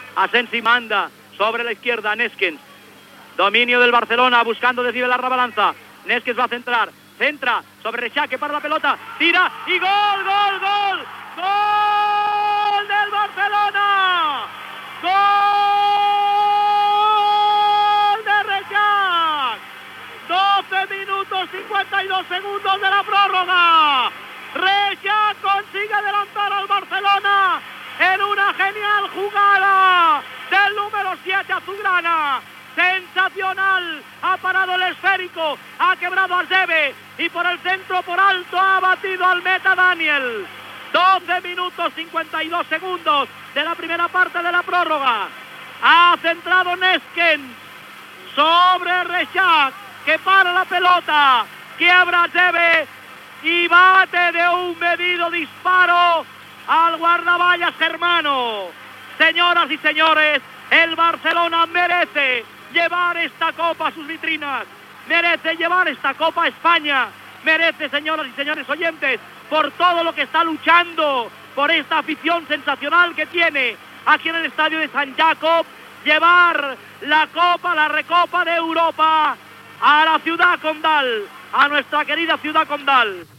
Ininici de la retransmissió de la final de la Recopa d'Europa de Futbol que el F.C:Barcelona juga, a Basilea, contra el Fortuna de Düsseldorf.
Descipció de l'arribada dels aficionats a la ciutat i de l'ambient del camp.
Entrevista a Rudy Ventura qui toca amb la trompeta "Endavant Barça" i narració de la sortida dels jugadors per fer el pre-escalfament.
Esportiu